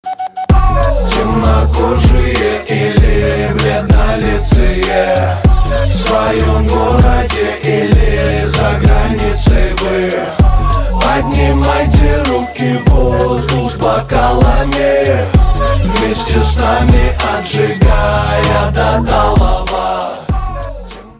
мужской вокал
Хип-хоп
русский рэп